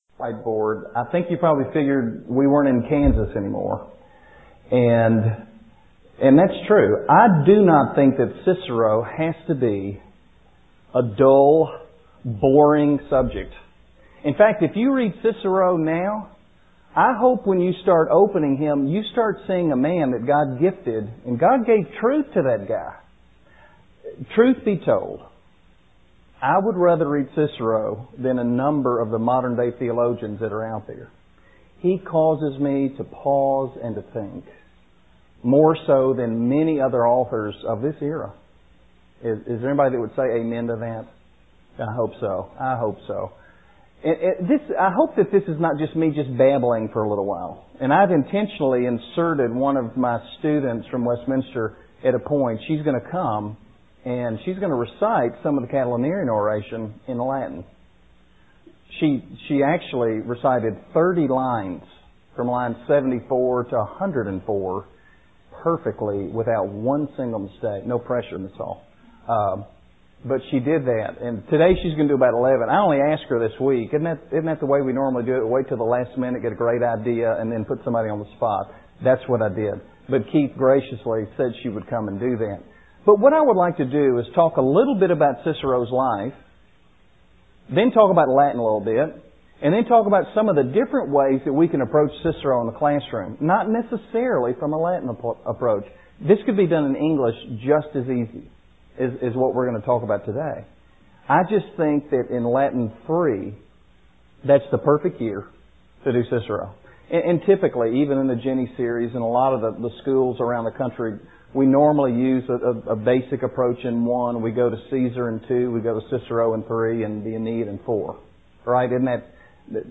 2005 Workshop Talk | 0:50:10 | All Grade Levels, Latin, Greek & Language
The Association of Classical & Christian Schools presents Repairing the Ruins, the ACCS annual conference, copyright ACCS.